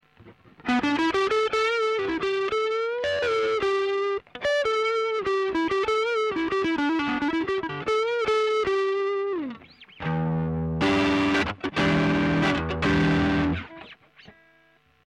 i recorded samples from my pedals to the point where its doin the "noise" and not necesserely to the max drive setting. the fender amp's EQ is set flat and recorded line coz couldnt with a mic(night time here). quality of the line is crap but still u can get the meaning. also i think it sounds lots more disturbing when hearing it directly from my amp. the speakers make it show more.
i also recorded a sample of my ts808 clone at max drive so u can compare that with the others as it comes for my guitar sound and amps "reactions".
i used the neck(single coil) to record the samples.
ts808.mp3